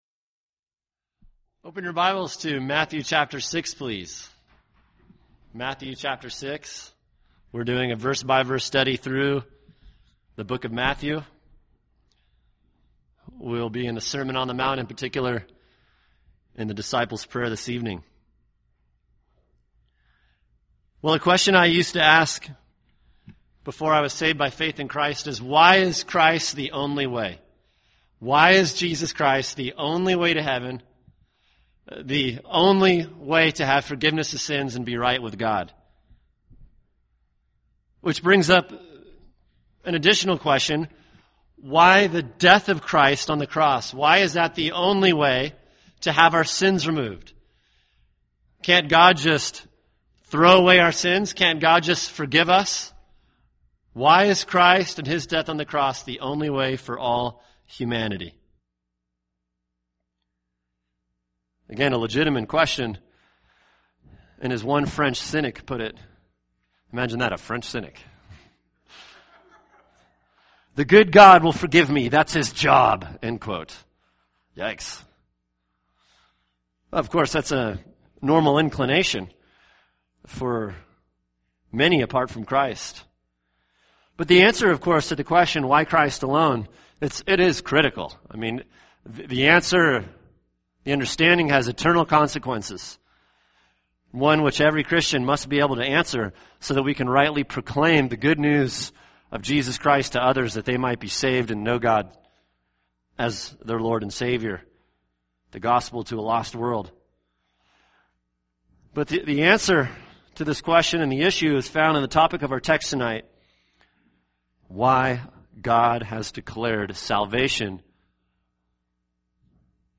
[sermon] Matthew 6:12 “How Can You Be Forgiven?”